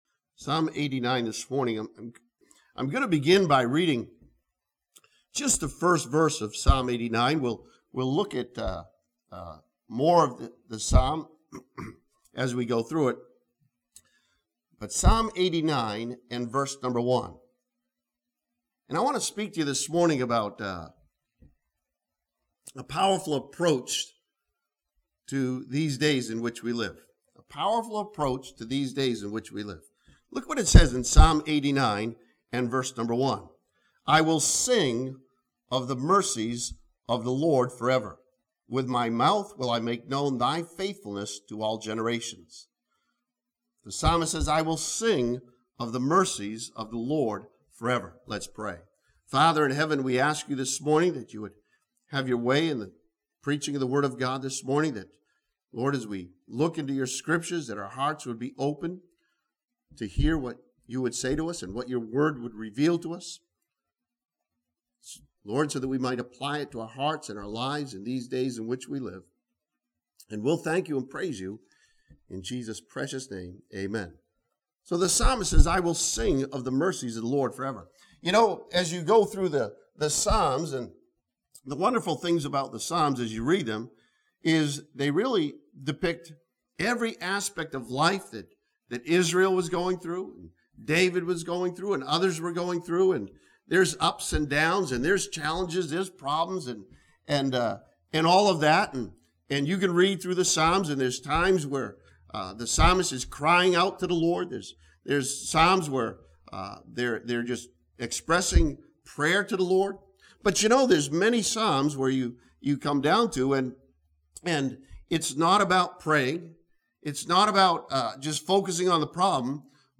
This sermon from Psalm 89 introduces a powerful approach to the problems that life brings to the Christian.